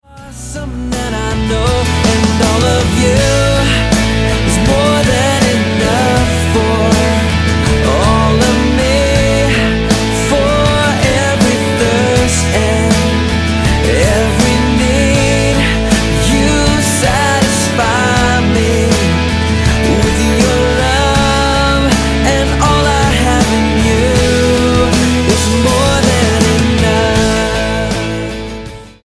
STYLE: Rock
surging bursts of rock guitar